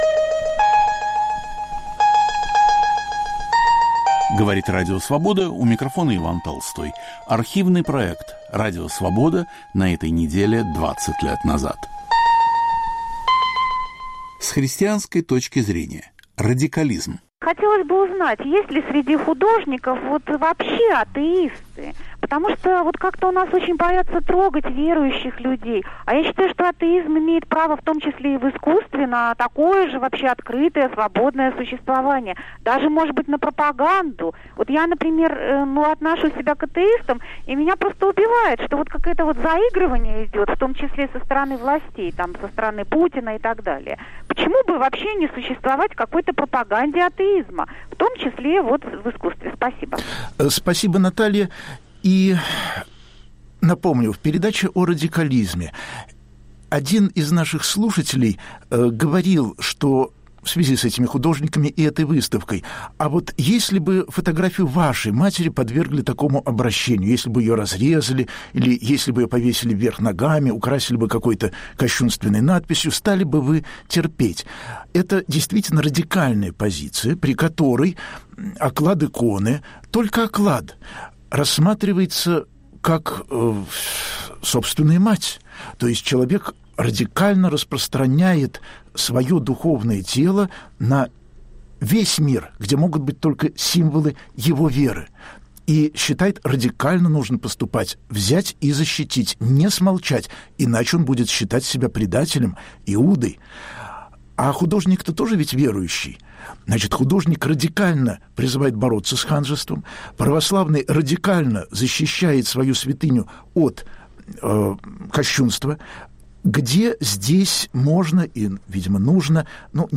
Гости в студии - художники и религиовед, и позвонившие слушатели обсуждают в прямом эфире радикализм в искусстве и выставку "Осторожно, религия!" в Сахаровском центре.